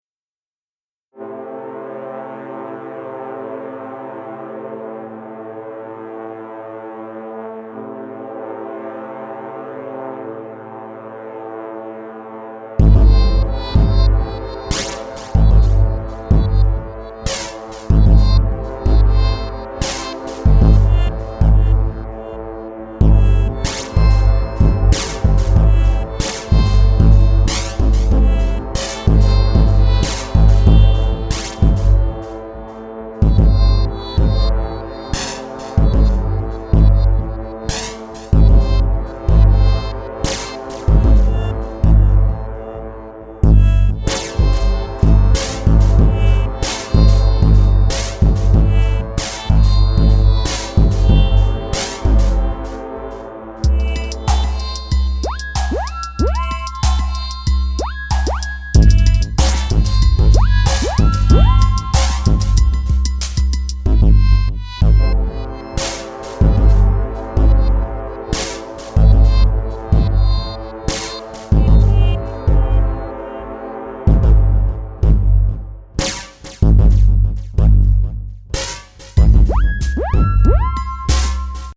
5 minute beat #2
I was doing everything with a sense of urgency and it was kinda like training lol BurningCrusade Filed under: Instrumental